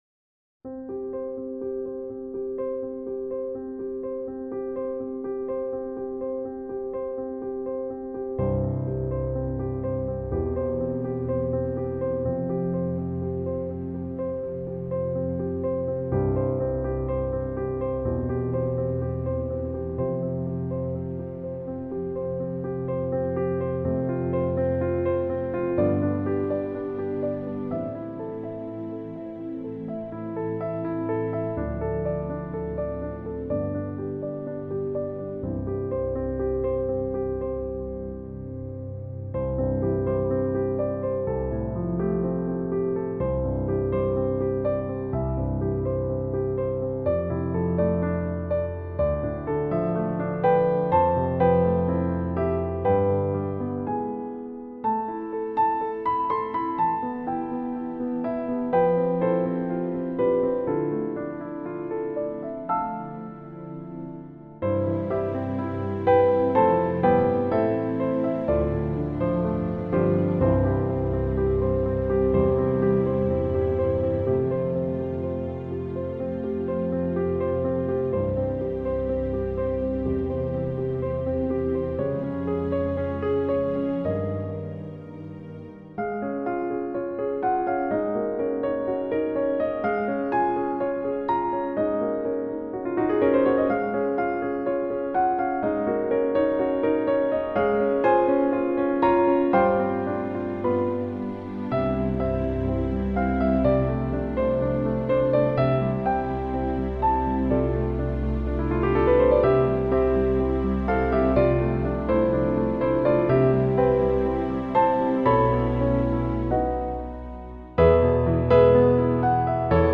A service for 7th February 2021